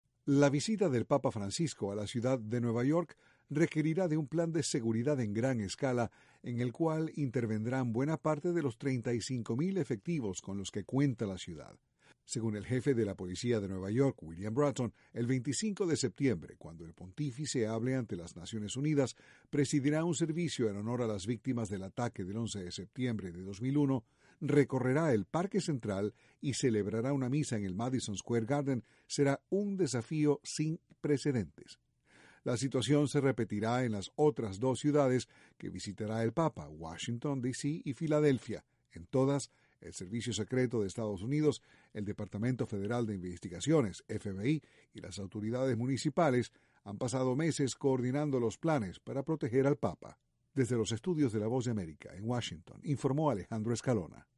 El Servicio Secreto, el FBI y buena parte de la policía de la ciudad de Nueva York estarán a cargo de la seguridad del Papa Francisco cuando visite esa ciudad en las próximas semanas. Desde la Voz de América, Washington, informa